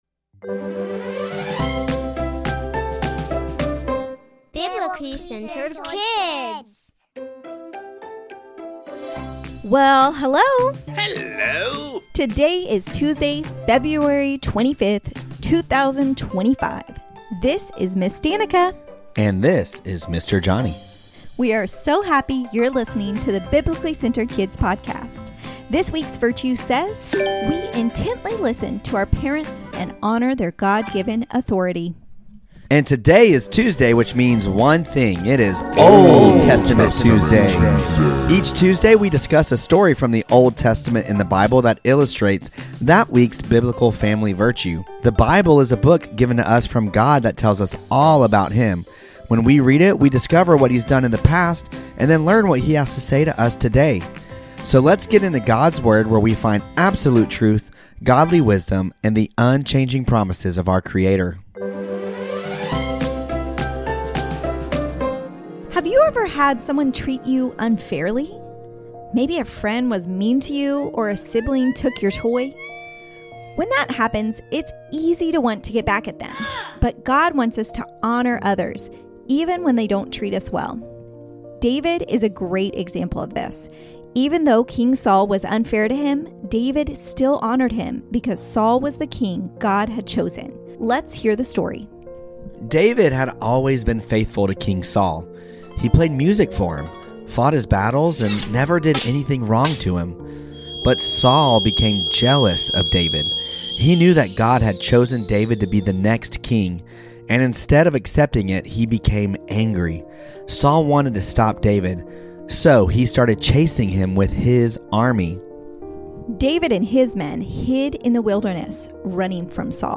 Tuesday: Old Testament Story | David Chooses Honor 🗡